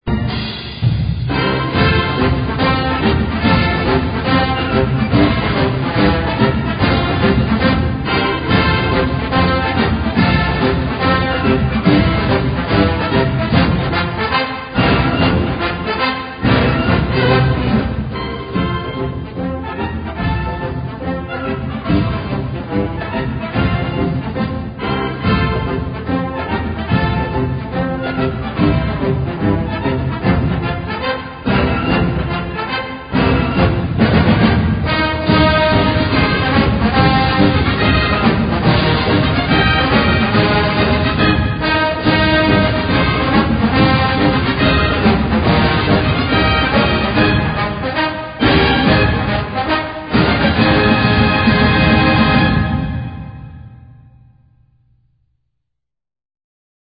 Since most theme music for colleges orginated in the 1920s and 30s, the sound is a traditional one with modernist tweaks: military band arrangements with ragtimey accent, typically played very quickly, often ripped through at lightspeed following big plays in games.
“Fight On” is a near-perfect example of what we tab as a classic fight song: pleasantly martial, with a thumping bass drum and a cheeky woodwind interlude in the middle, it could be a fight song or the theme to a WWII caper movie starring David Niven and Lee Marvin as paratroopers leading an impossible mission behind enemy lines.